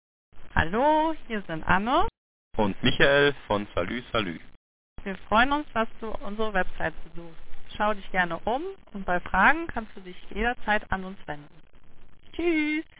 Anbei haben wir eine kleine Nachricht für euch aufgenommen mit einem unserer Audio Gästebücher, einem Wählscheibentelefonen aus den 1970s bis 1980s Jahren.